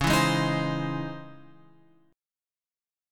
C#mM9 chord {9 7 6 8 x 8} chord